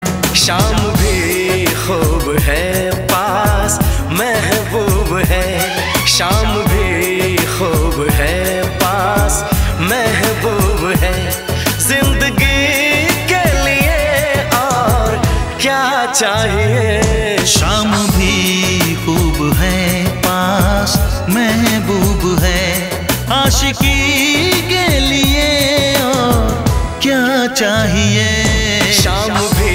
A soulful romantic Hindi tone for evening vibes.